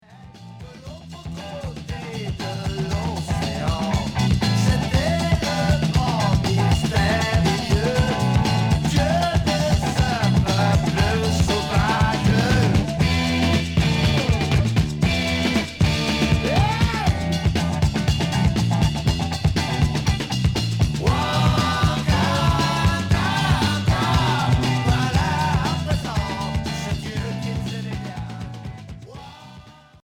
Progressif Deuxième 45t retour à l'accueil